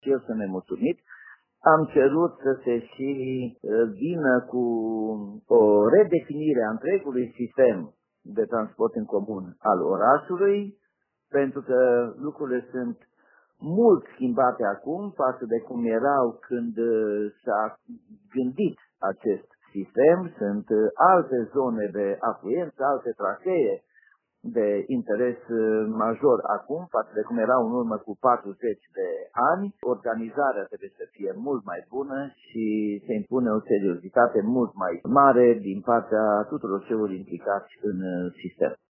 La rândul său, primarul Timișoarei Nicolae Robu spune că timișorenii au toate motivele să se plângă de RATT. Edilul consideră că reclamațiile timișorenilor care au sesizat în cadrul rubricilor interactive de la Radio Timișoara probleme cu ale sistemului de transport în comun sunt justificate: